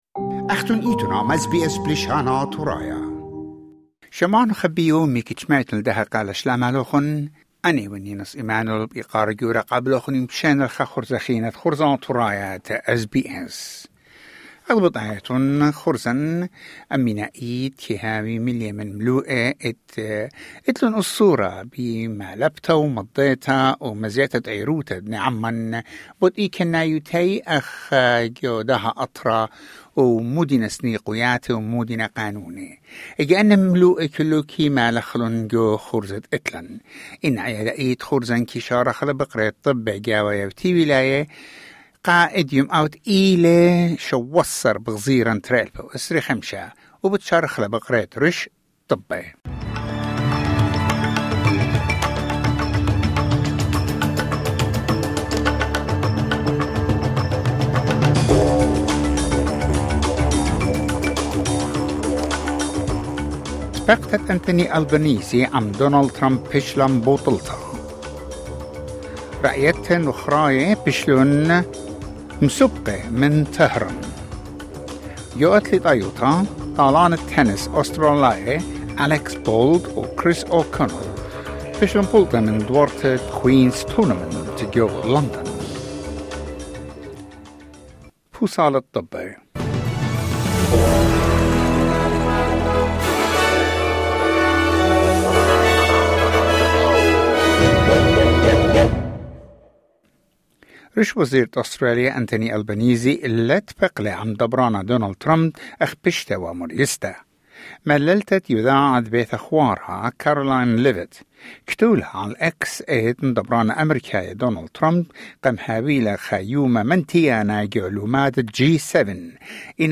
SBS Assyrian news bulletin: 17 June 2025